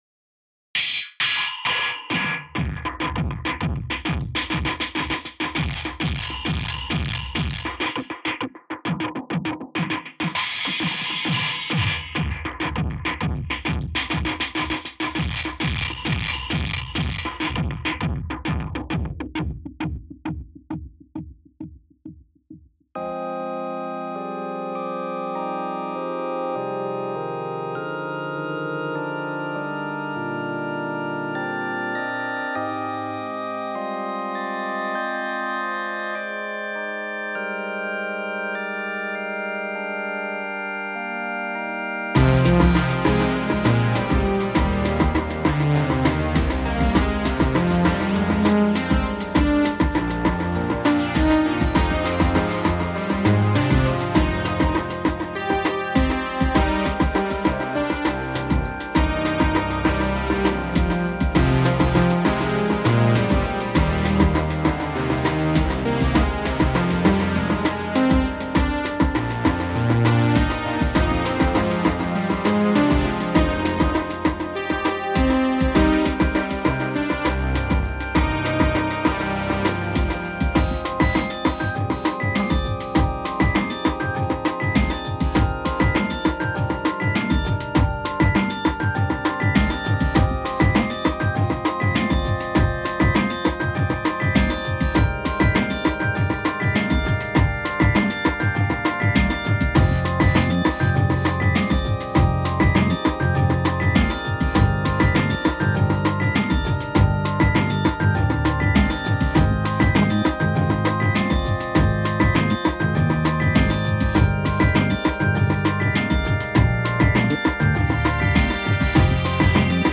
These "songs" are just the results of me playing with Renoise. I usually drop some sounds to the timeline and listen to them looped.